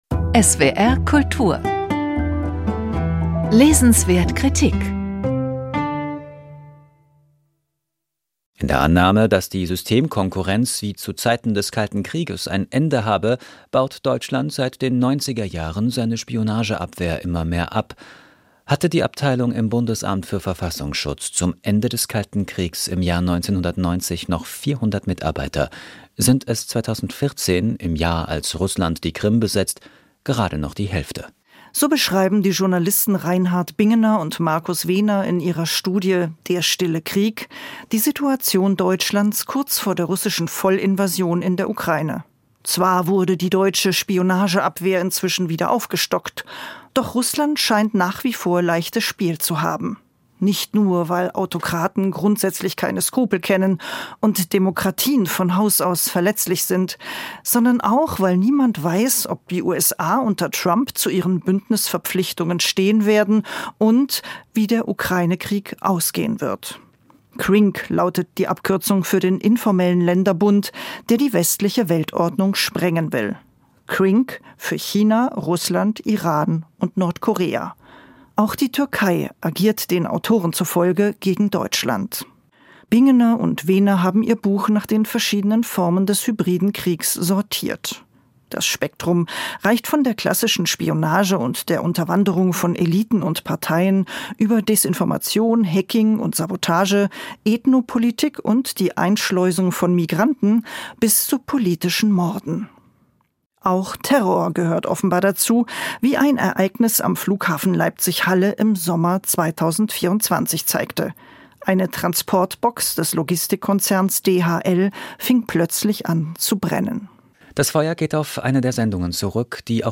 Reinhard Bingener, Markus Wehner – Der stille Krieg. Wie Autokraten Deutschland angreifen | Buchkritik